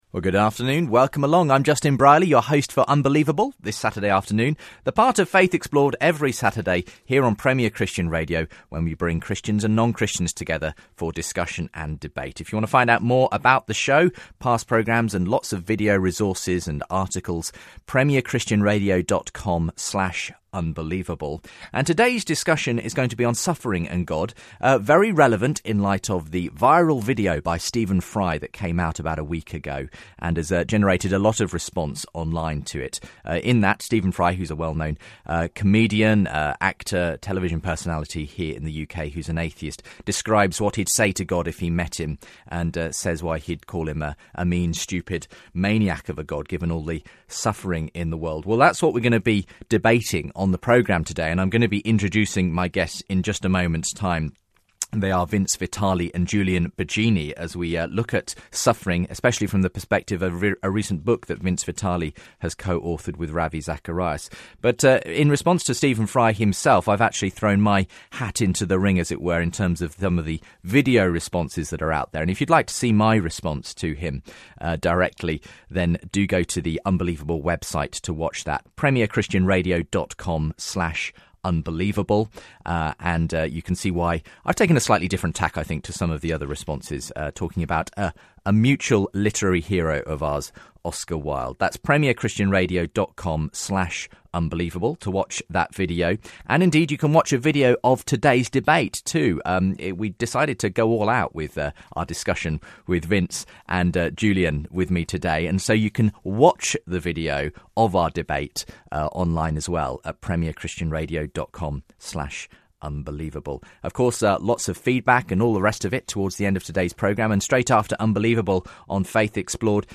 faith debates